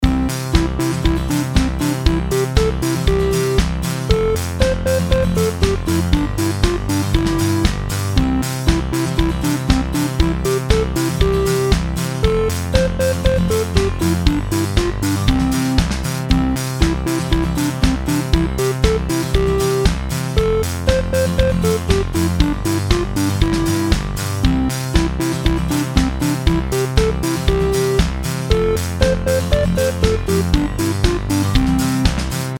LOOP推奨： LOOP推奨
楽曲の曲調： MIDIUM
楽曲紹介文： 良く分からないがワクワクする時のBGM等に
明るい  コミカル